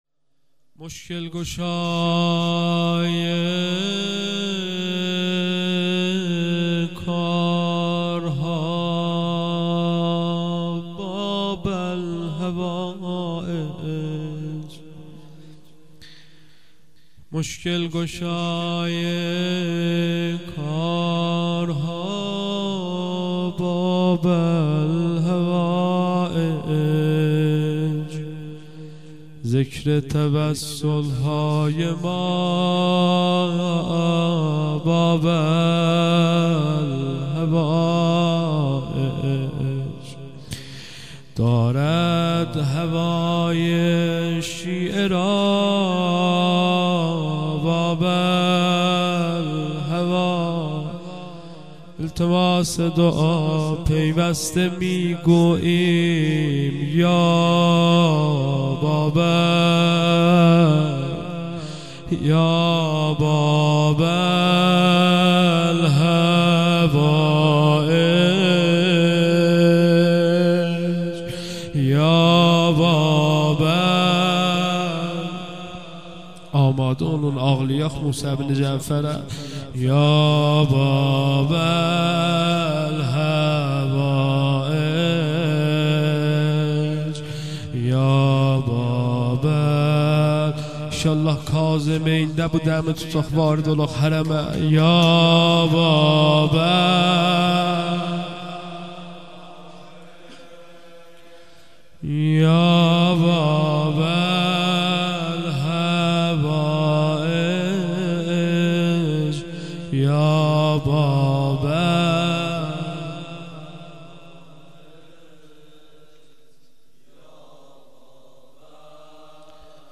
روضه امام موسی بن جعفر ع.mp3